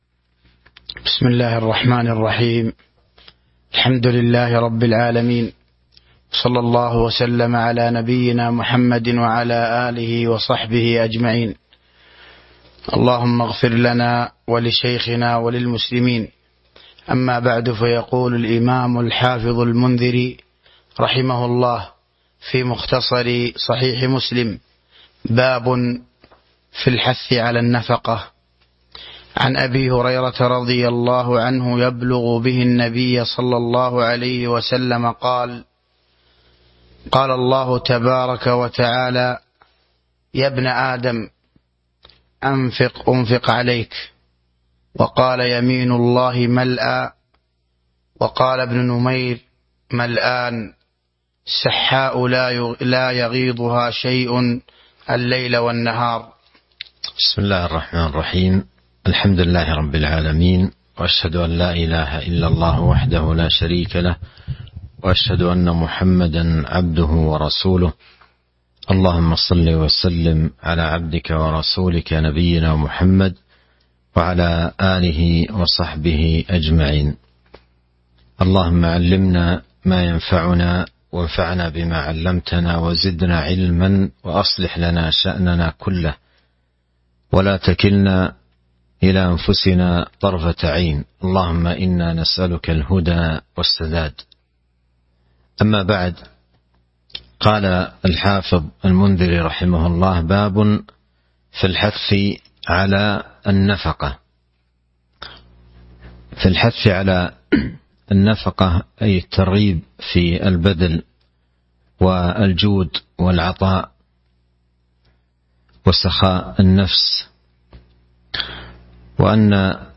تاريخ النشر ١٧ رجب ١٤٤٢ هـ المكان: المسجد النبوي الشيخ